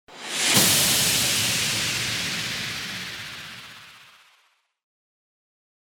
FX-1001-WHOOSH-PACT
FX-1001-WHOOSH-PACT.mp3